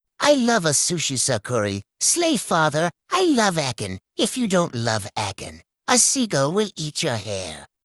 Cartoon_Male_Manzilla.wav